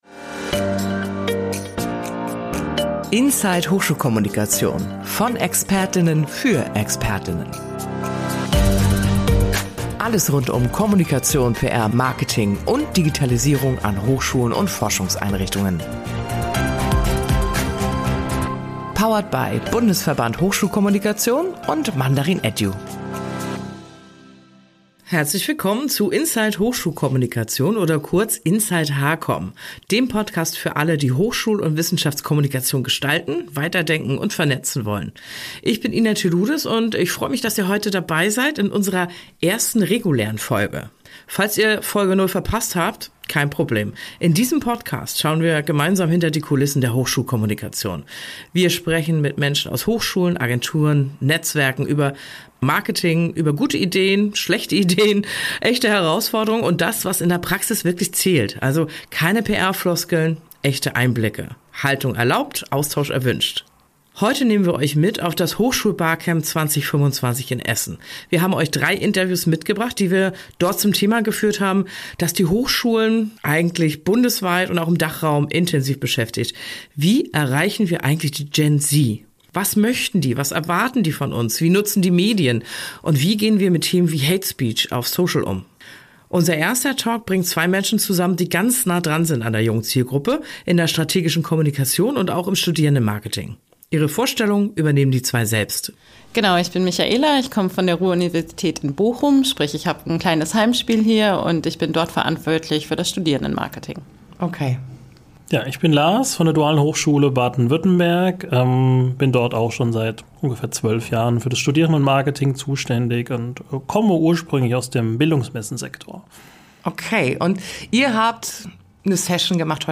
Wie können Hochschulen die Generation Z wirklich erreichen – auf Augenhöhe und den richtigen Kanälen? In Folge 1 von “Inside HKomm” nehmen wir euch mit zum Hochschulbarcamp 2025 in Essen und hören, was Expert:innen direkt aus der Praxis berichten.